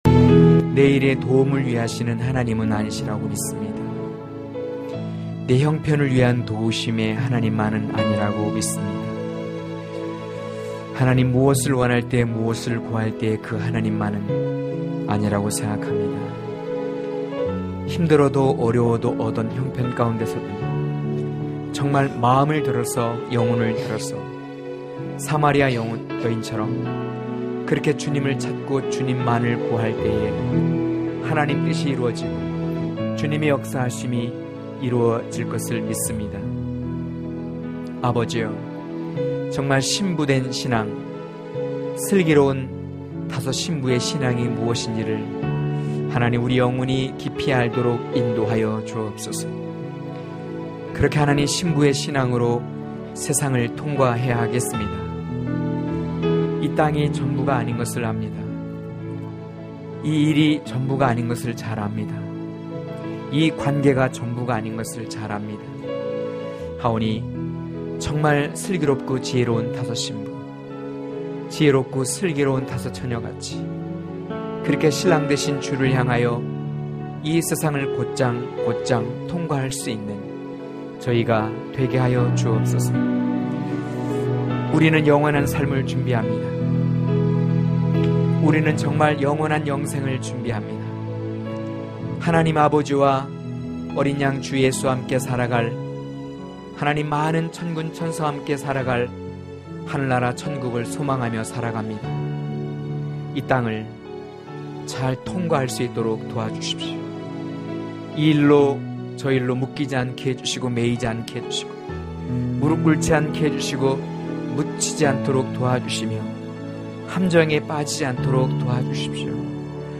강해설교 - 16.성도들 가운데서 신부(아6장5-13절)